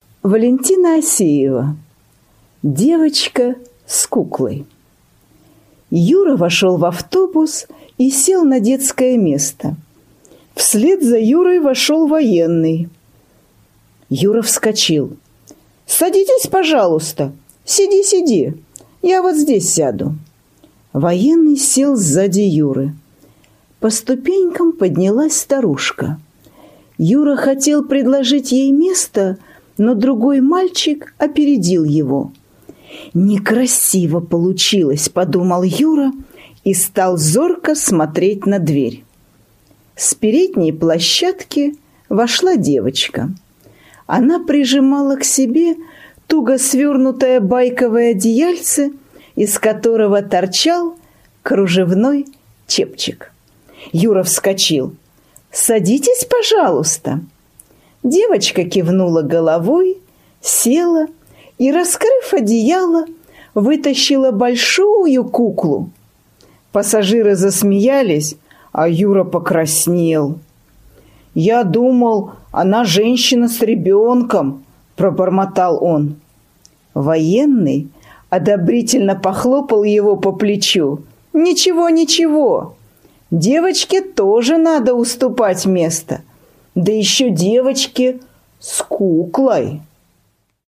На данной странице вы можете слушать онлайн бесплатно и скачать аудиокнигу "Девочка с куклой" писателя Валентина Осеева.